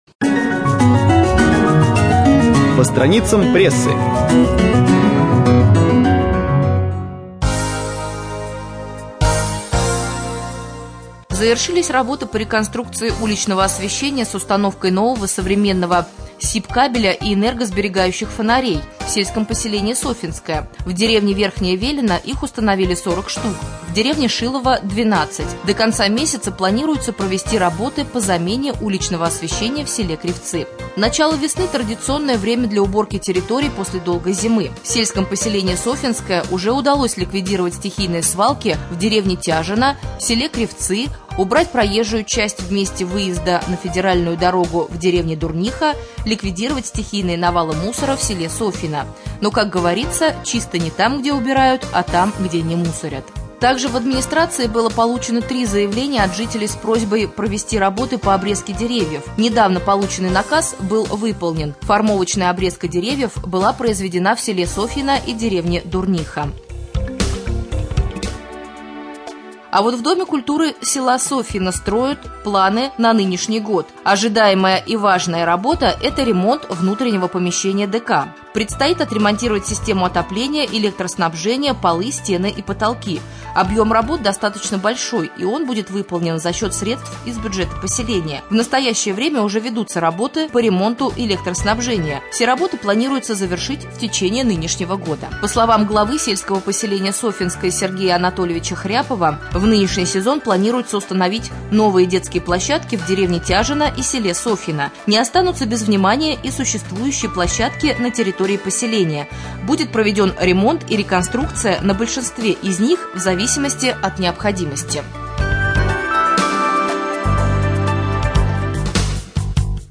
01.04.2014г. в эфире раменского радио - РамМедиа - Раменский муниципальный округ - Раменское